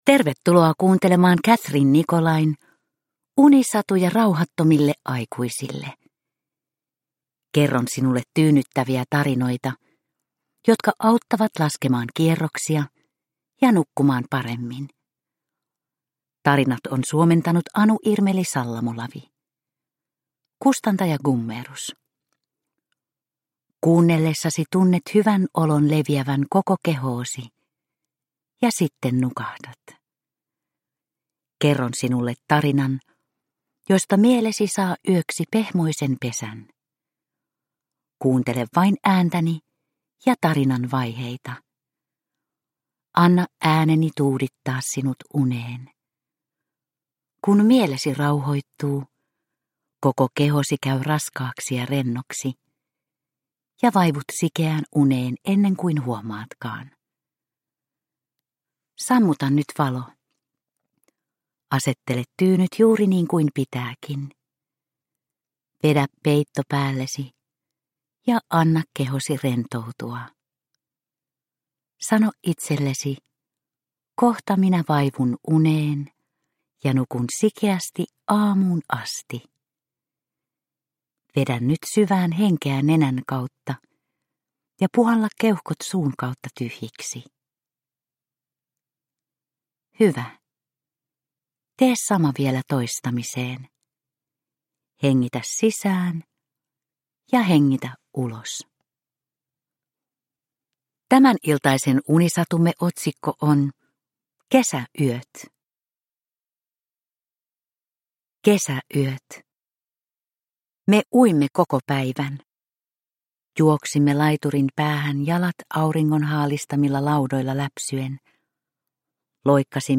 Unisatuja rauhattomille aikuisille 38 - Kesäyöt – Ljudbok – Laddas ner